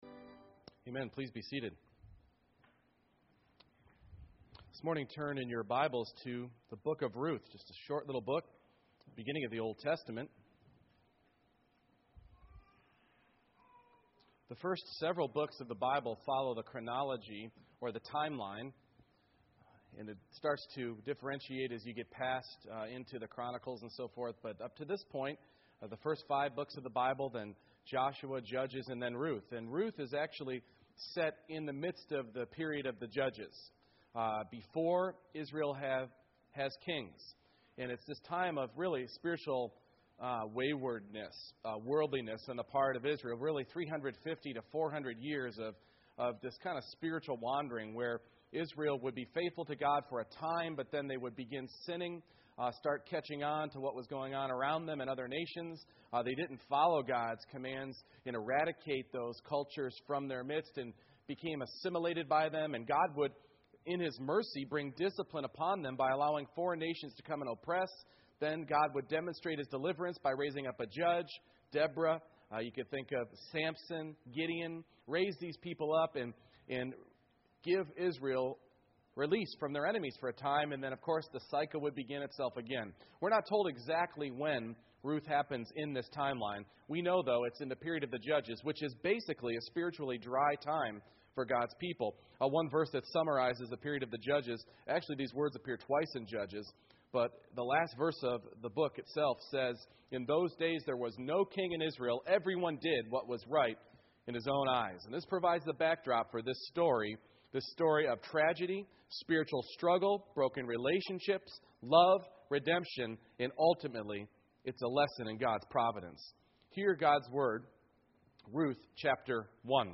Ruth 1:1-22 Service Type: Morning Worship Introduction